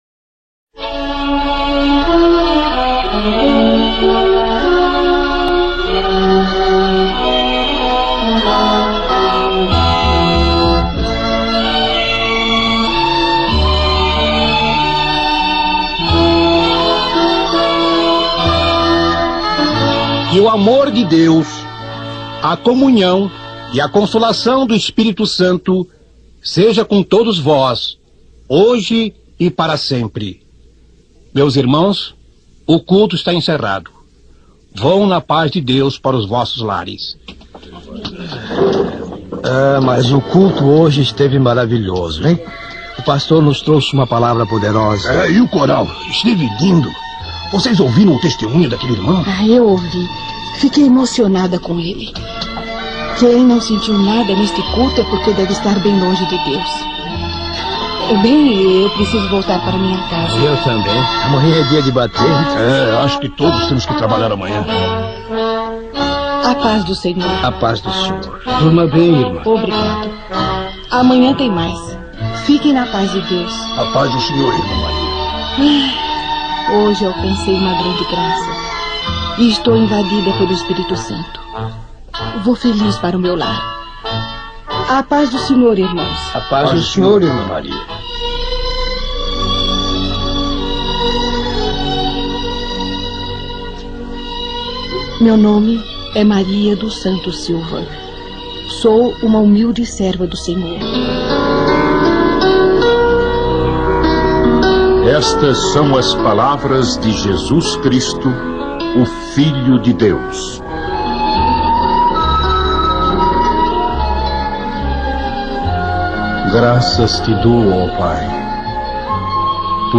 Esse é um poderosíssimo e edificante testemunho de uma irmã que alcançou graça diante dos olhos do SENHOR, obtendo d’Ele algo que todo crente fiel em JESUS CRISTO sonha alcançar, que é de contemplar as moradas eternas no céu, e de vê-lO como ELE é.